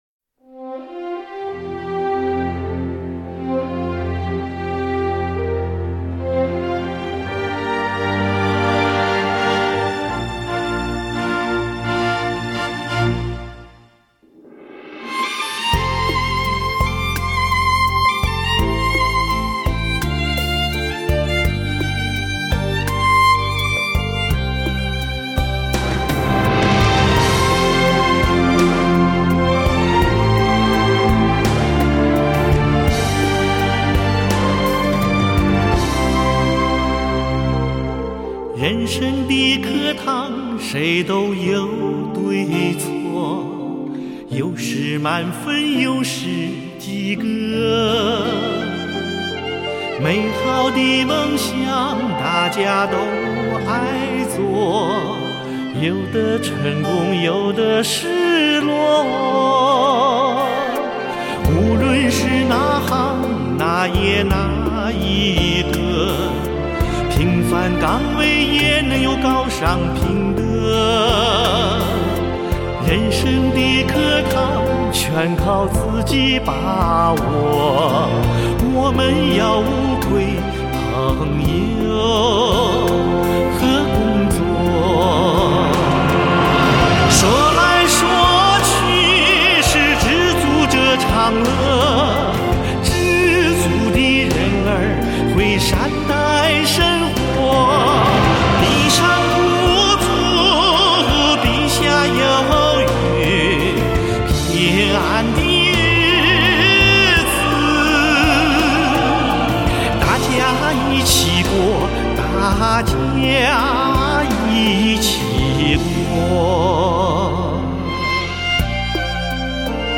国家一级演员、著名歌唱家。
讲究歌词 词意的表达和旋律的韵味，音色甜润醇厚，吐字清晰。